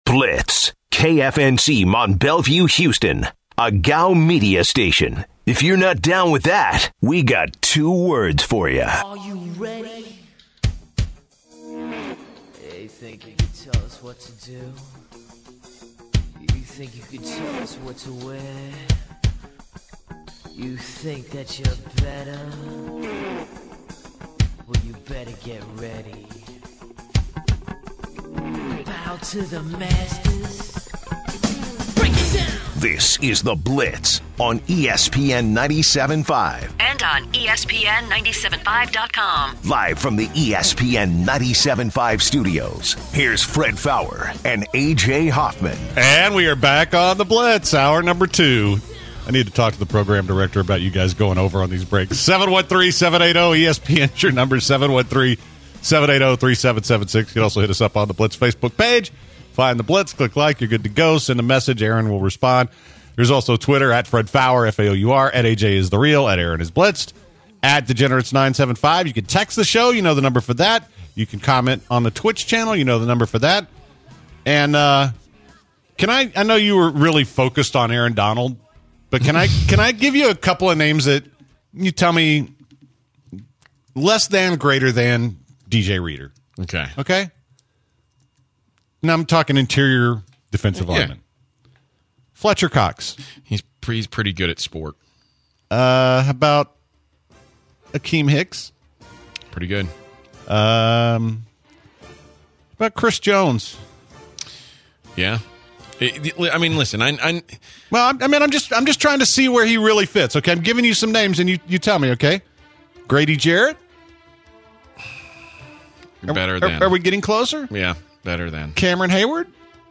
Should the Texans be aggressive and go all-in to get to the Super Bowl? A caller asks about recent NFL bets that he placed in Vegas.